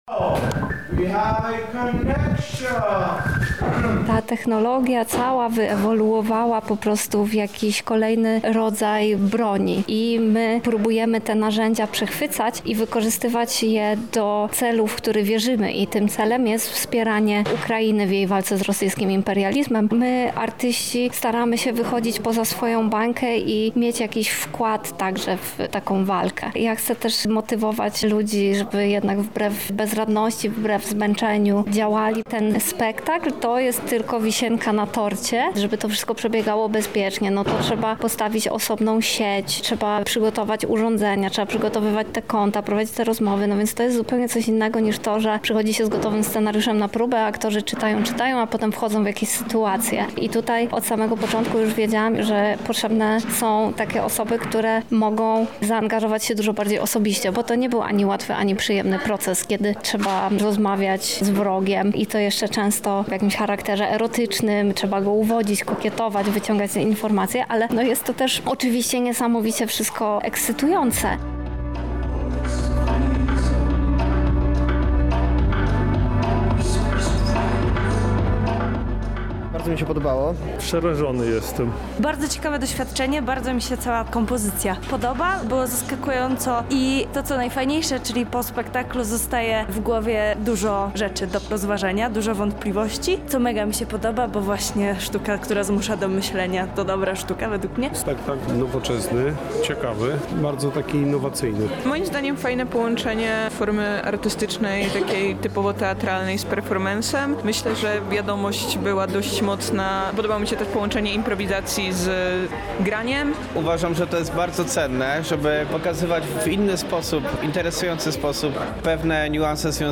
Spy Girls, relacja, polska premiera spektaklu